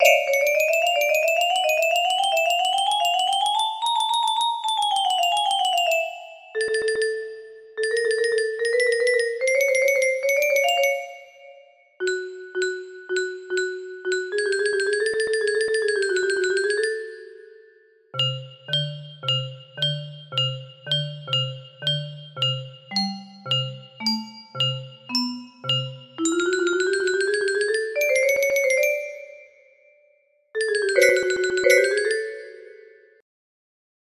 Smiley Face music box melody